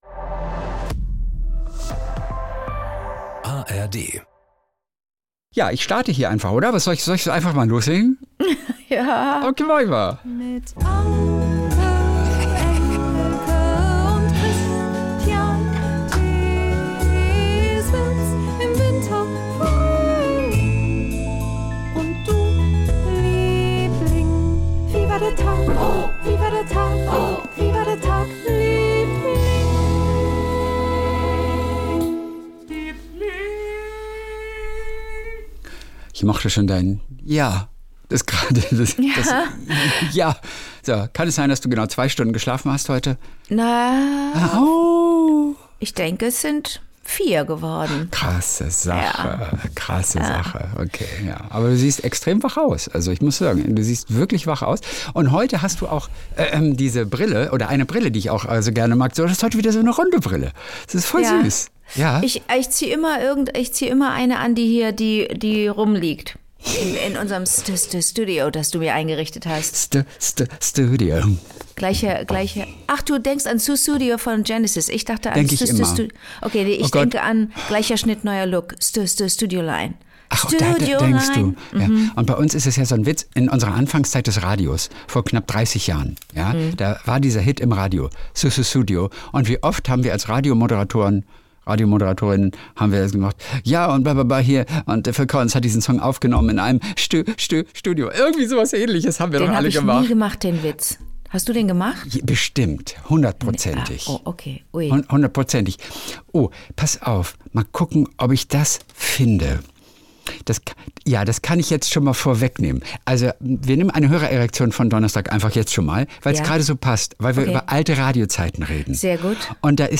Die kleinen Geschichtchen des Tages mit Anke Engelke und SWR3-Moderator Kristian Thees.